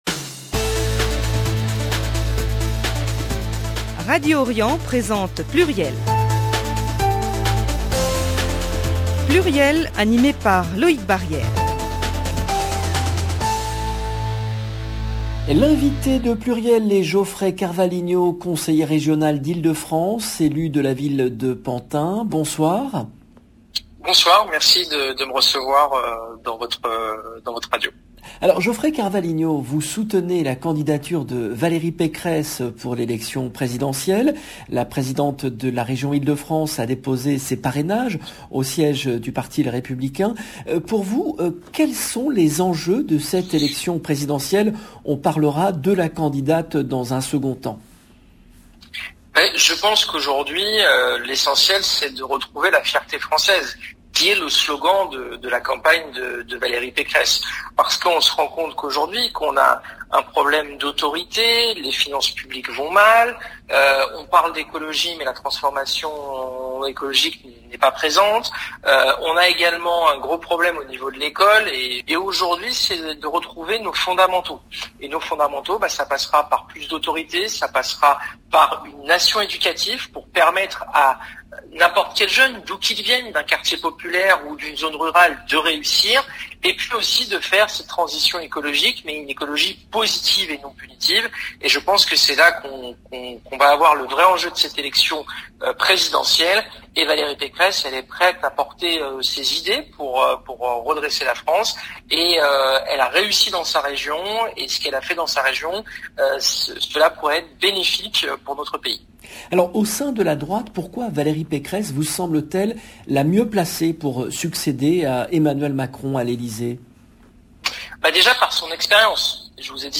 PLURIEL, émission diffusée le vendredi 29 octobre 2021
L’invité de PLURIEL est Geoffrey CARVALHINHO , conseiller Régional d’Île-de-France, élu de la ville de Pantin, soutien de Valérie Pécresse, candidate à l'élection présidentielle. L'élu de Pantin évoque les propositions économiques de Valérie Pécresse et ses idées pour financer la transition écologique.